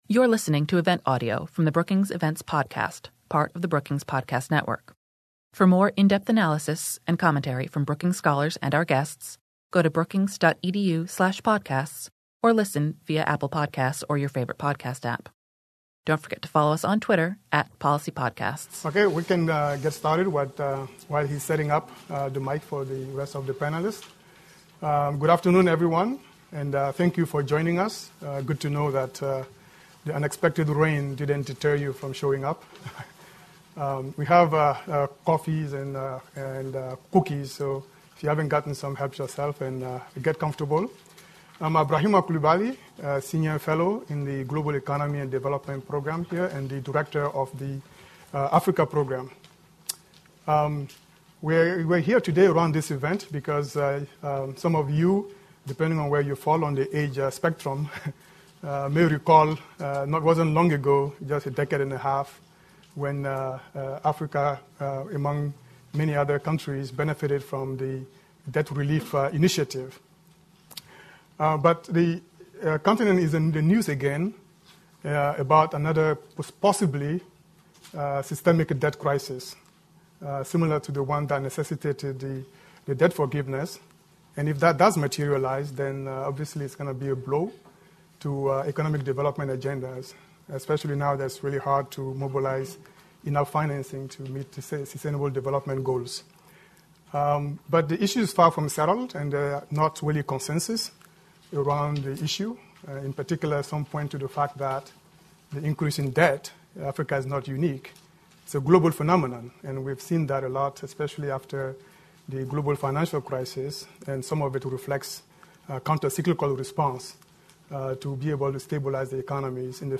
On April 12, the Africa Growth Initiative at the Brookings Institution hosted a high-level panel to discuss debt sustainability in Africa.